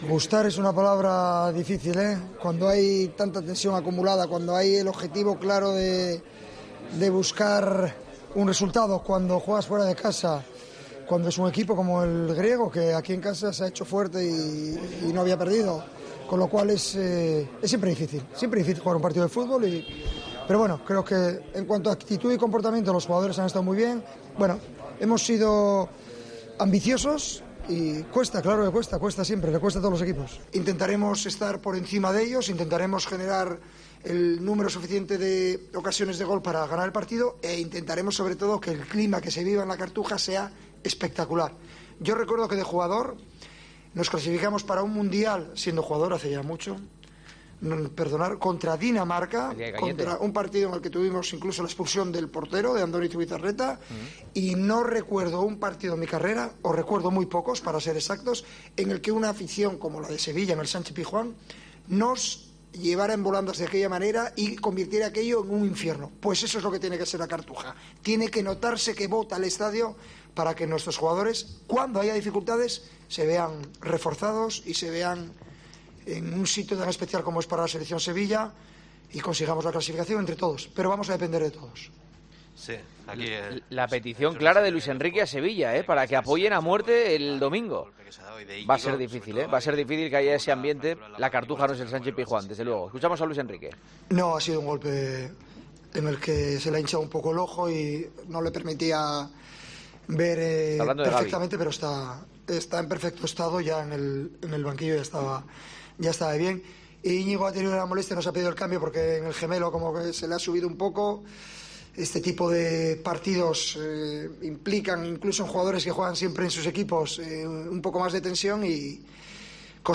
AUDIO: El seleccionador ha comparecido en rueda de prensa tras el triunfo por la mínima de España ante Grecia. "Estaremos en el Mundial", ha dicho.